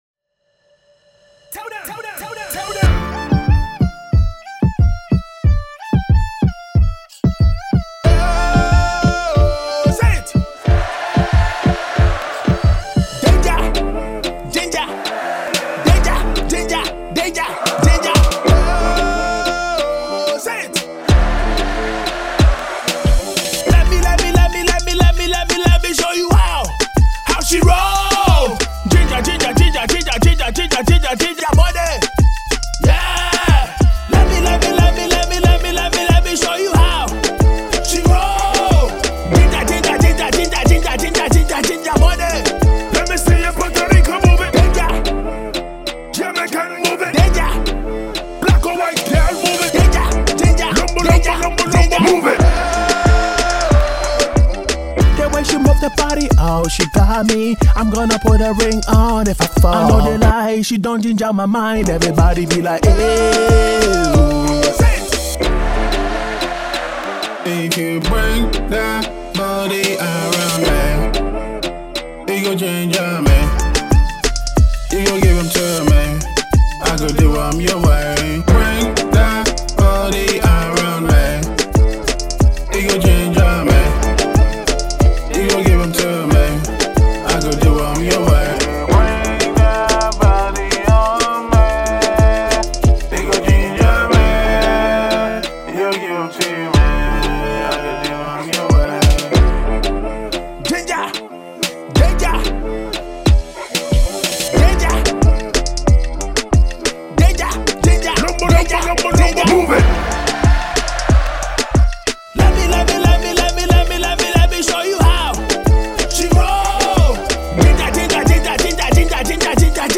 Afro-Swing and Afrobeats
all wrapped in a beat that guarantees movement.